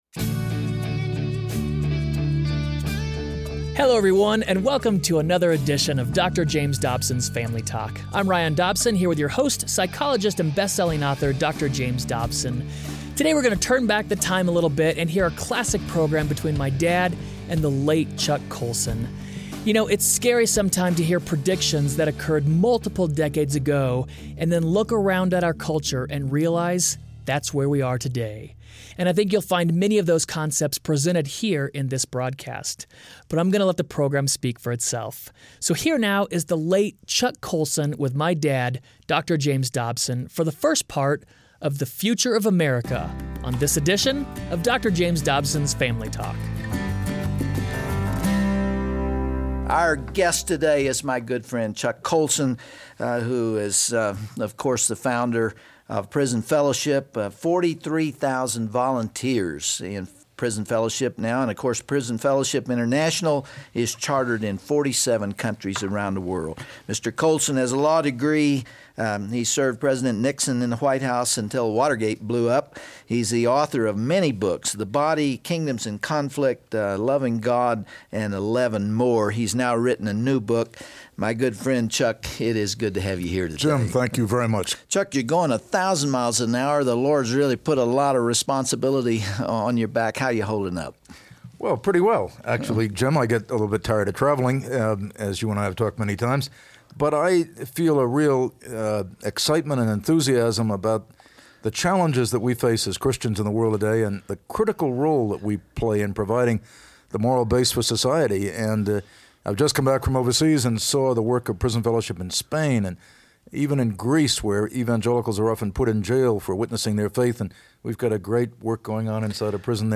On this classic edition of FamilyTalk, the late Chuck Colson and Dr. Dobson talk about the future of America.
Host Dr. James Dobson